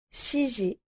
• しじ
• shiji